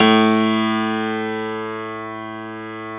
53f-pno05-A0.aif